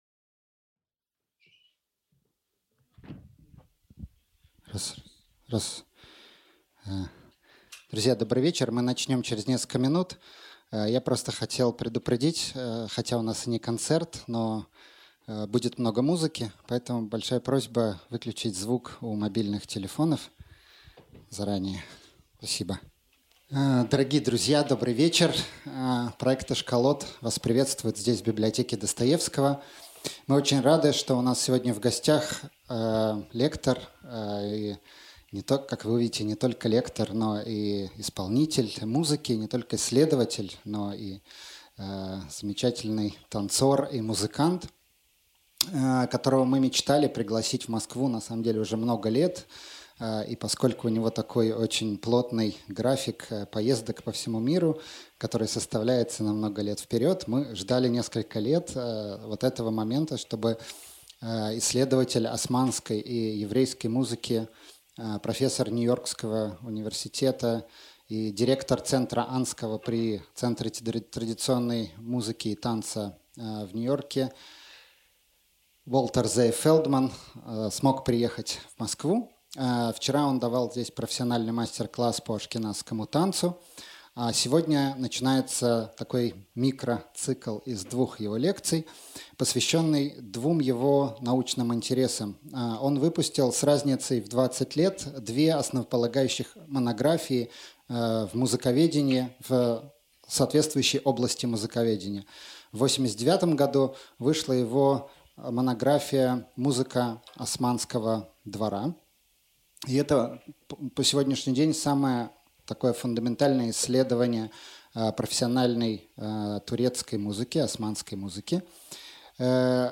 Aудиокнига Османская и ашкеназская музыка Автор Фелдман Уолтер Зев.